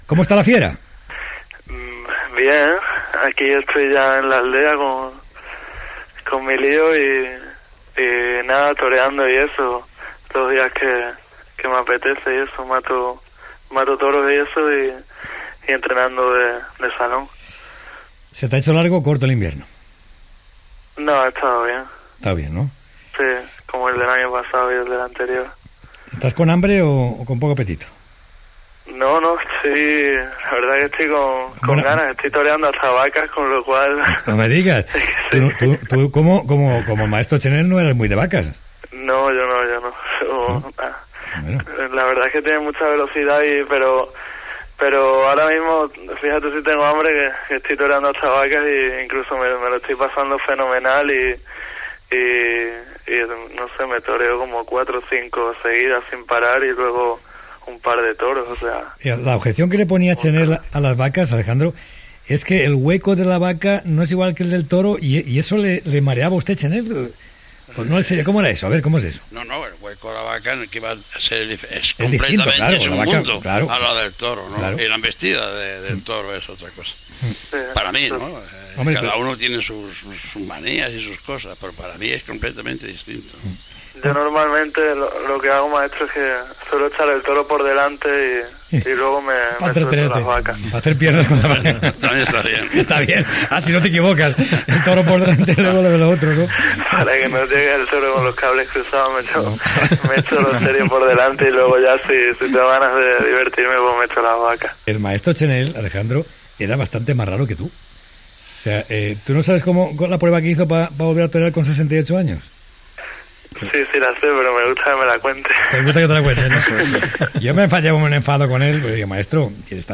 Entrevista al torero Alejandro Talavante que prepara la seva nova temporada.
Informatiu